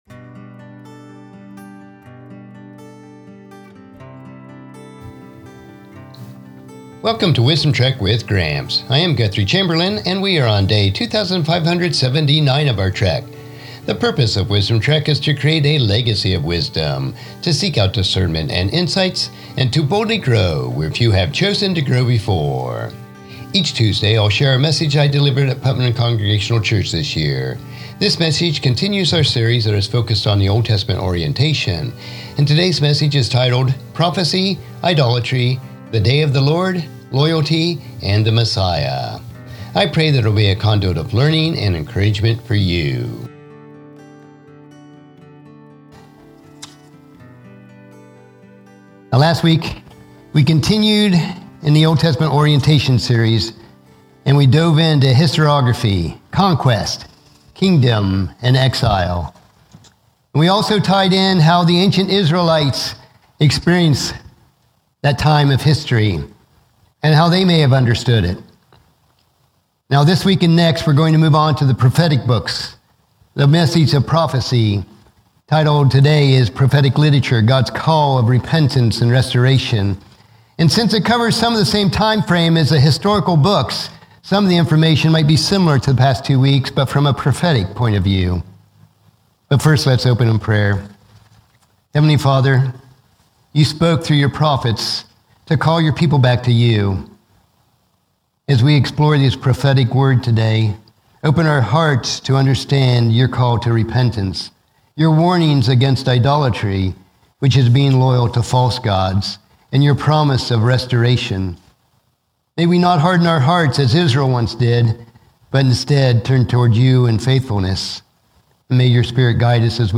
Sermon Series: Old Testament Orientation Message 8: Prophetic Literature – God’s Call to Repentance and Restoration